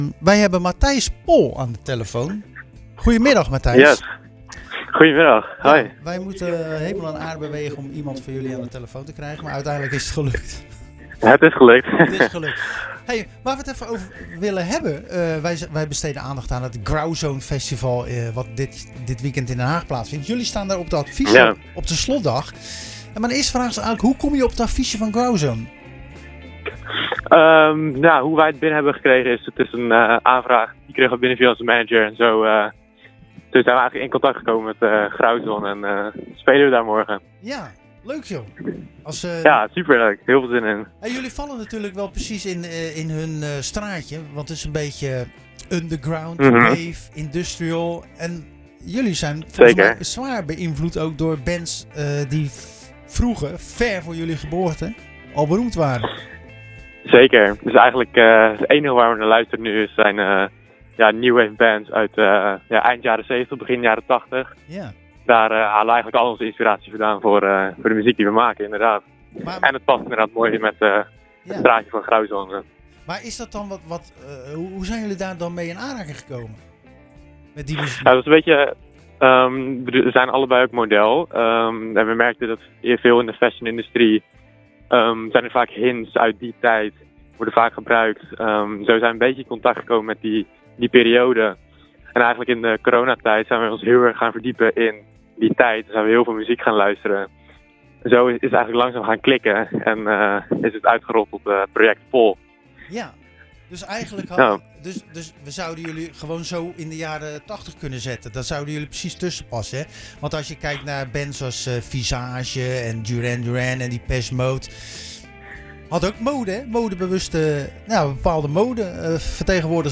Tijdens de wekelijkse editie van Zwaardvis bespraken we met de band Pol uit Alphen a/d Rijn het Grauzone Festival in Den Haag. Dit is een jaarlijks multidiciplinair festival in Den Haag dat gedurende drie dagen zijn 10-jarig jubileum vierde.��Pol is ge�nspireerd door de new wave muziek uit de jaren tachtig waarbij naast muziek ook mode een belangrijke rol speelt.